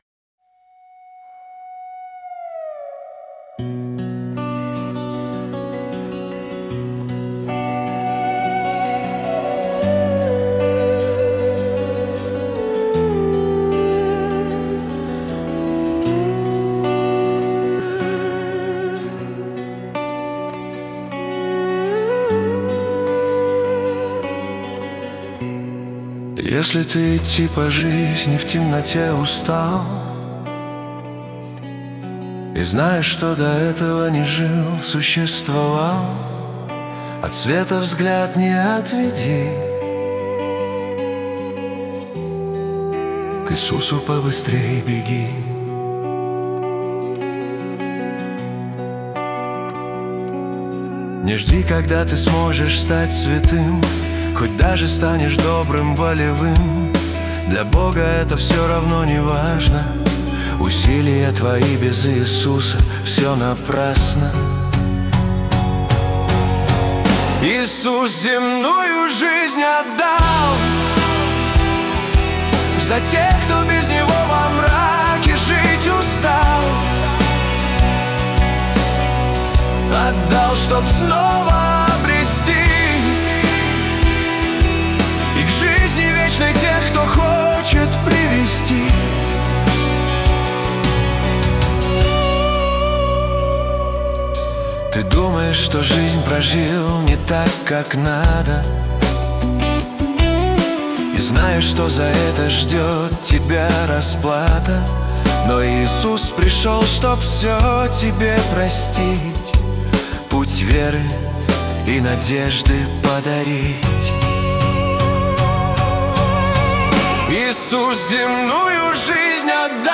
песня ai
274 просмотра 731 прослушиваний 100 скачиваний BPM: 76